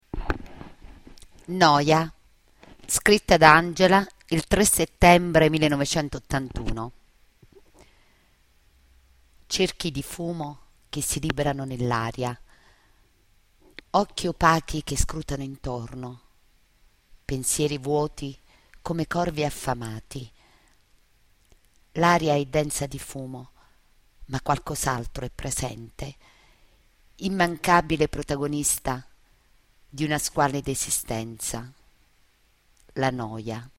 Poesie recitate da docenti